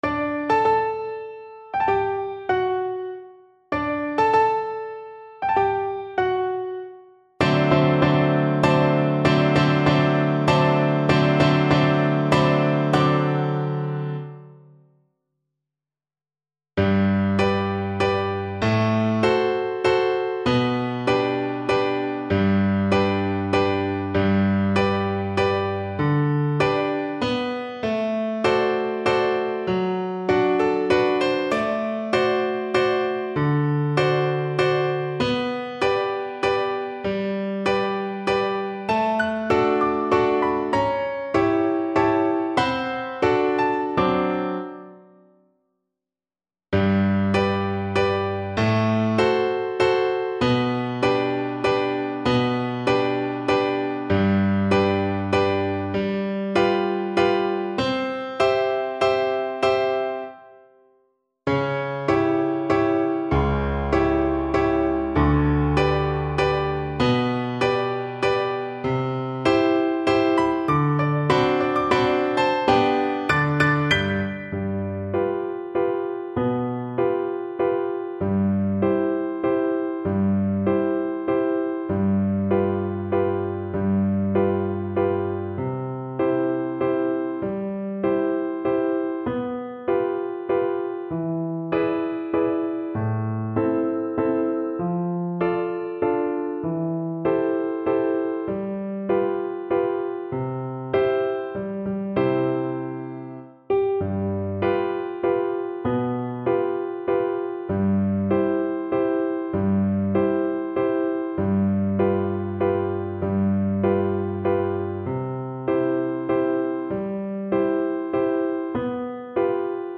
Allegre brilhante =148
3/4 (View more 3/4 Music)
Classical (View more Classical Flute Music)